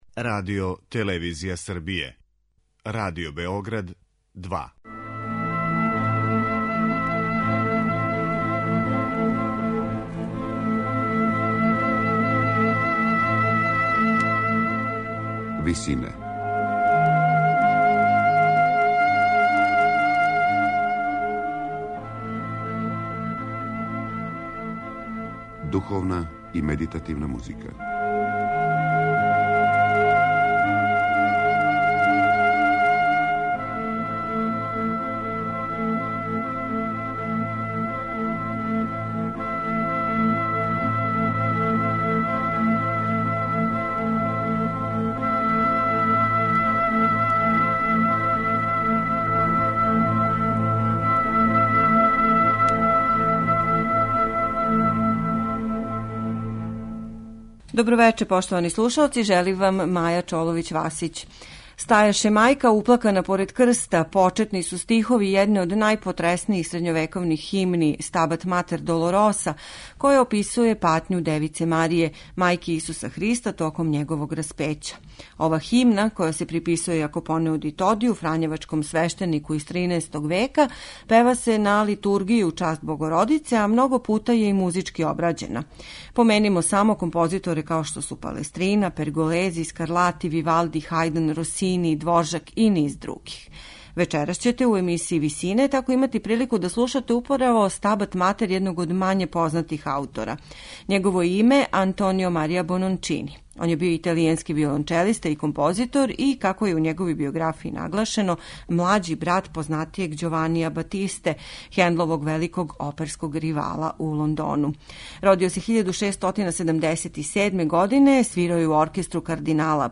Извођачи су чланови хора катедрале Сент Џон (St. John) у Кембриџу и камерни оркестар Филомузика из Лондона.